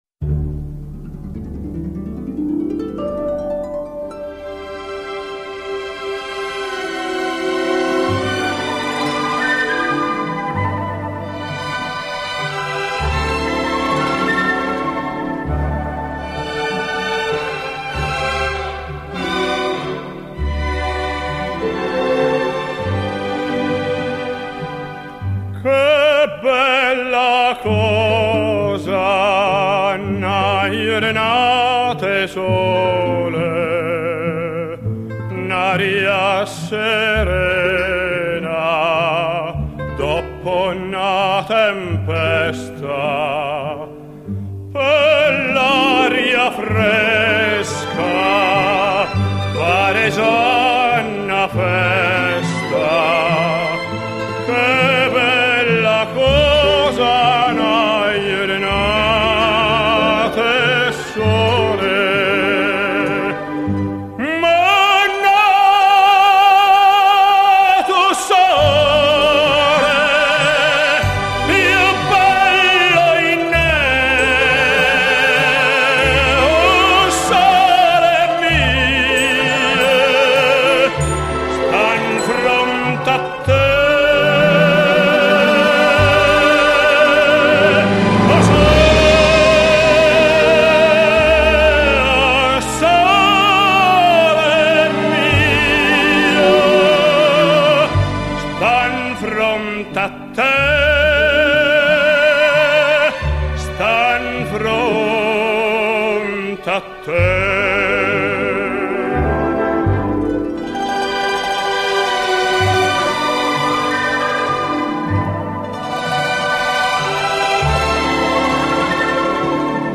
由于原录音是1962